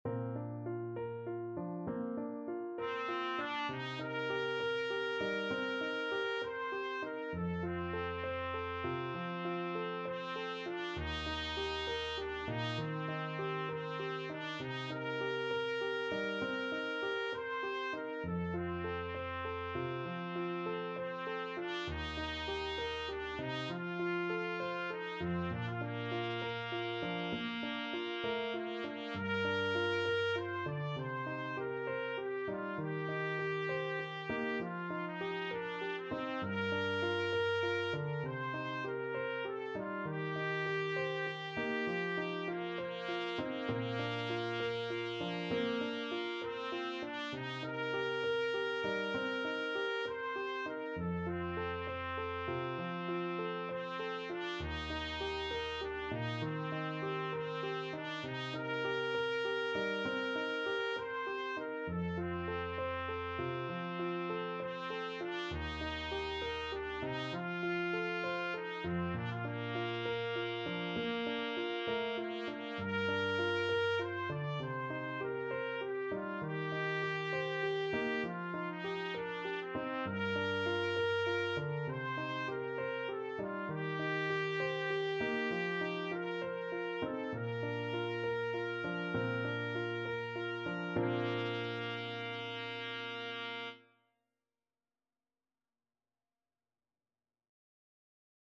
6/8 (View more 6/8 Music)
Bb4-D6
Classical (View more Classical Trumpet Music)